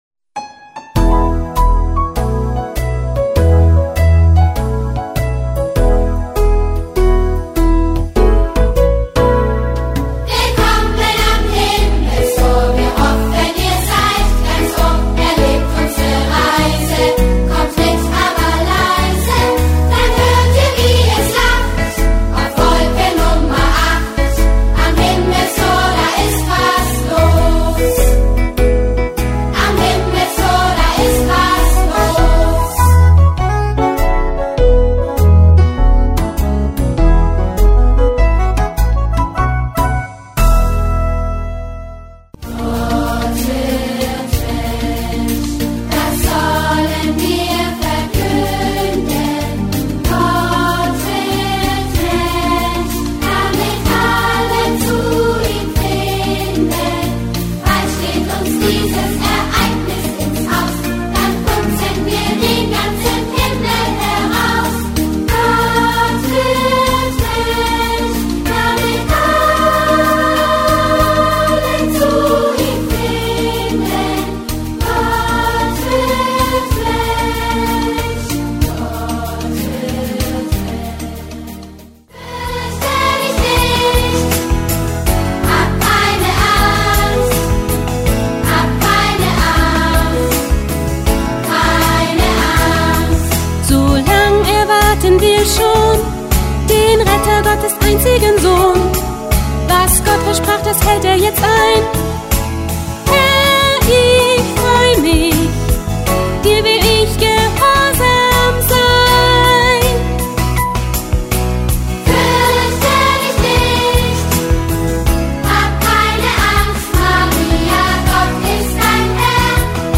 Medley Weihnachts-Musical 2012 als mp3
Die Großen, die früher im Chor sangen haben den K.J.Chor St.Rupert gesanglich hervorragend unterstützt.
Die Kinder waren aber mit Leidenschaft dabei und konnten diesmal in 55 Minuten zeigen, dass Singen wirklich Spaß macht.